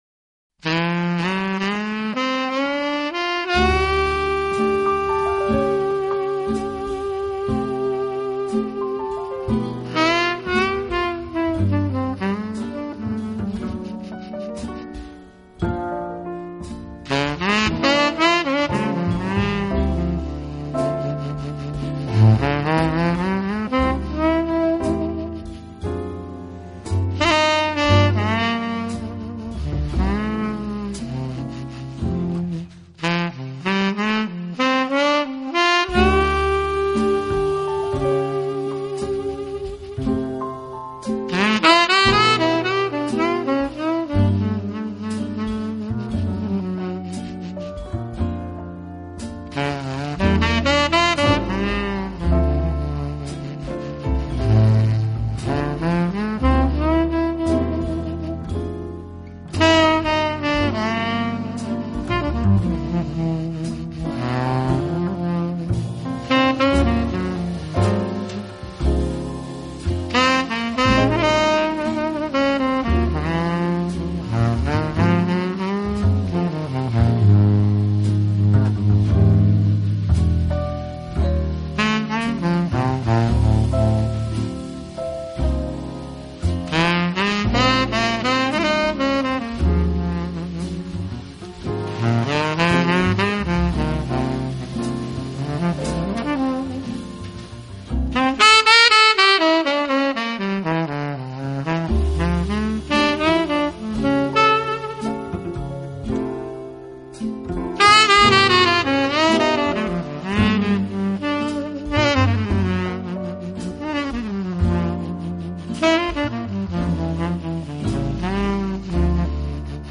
音乐类型：Jazz
萨克斯独特的魅力，舒放的温柔，优雅的旋律，犹如少女般的轻柔，娇羞，唯美
浪漫的节奏感和姿意奔放的旋律叩人心扉。